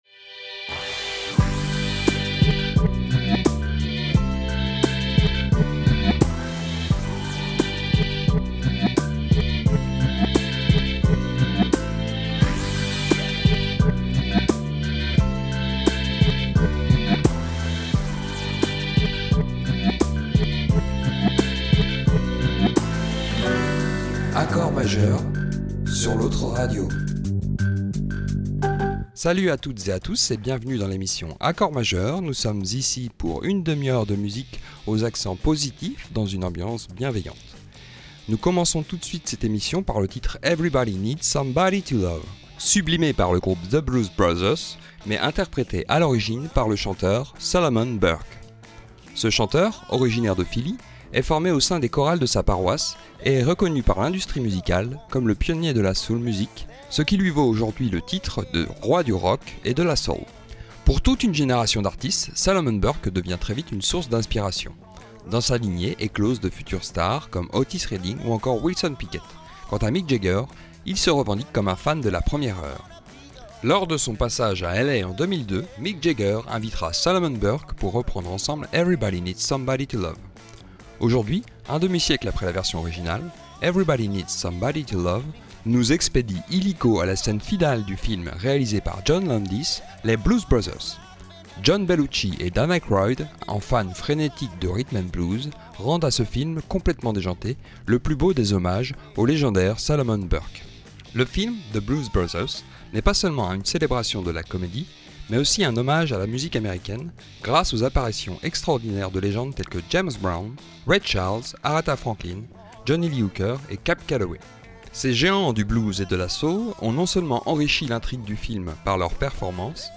Recycler l’eau des piscines : des campings des Pays de la Loire s’engagent